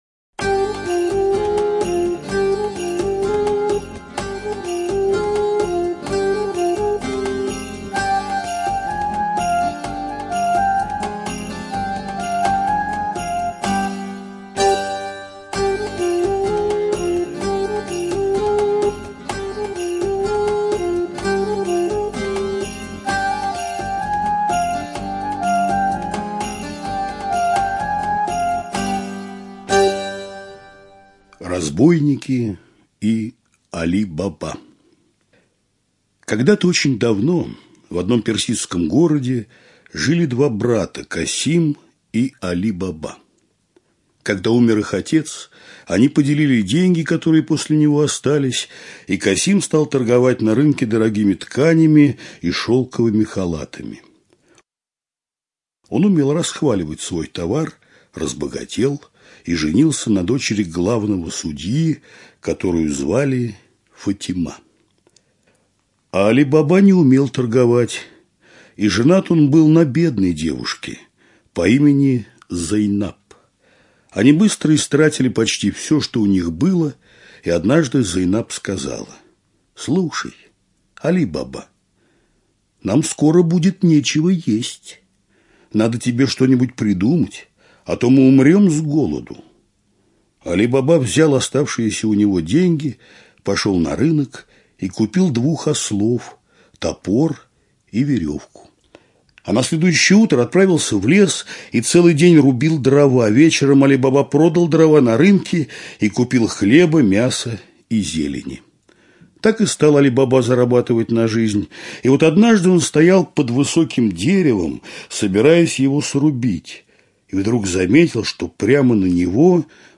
Али Баба и сорок разбойников - восточная аудиосказка - слушать онлайн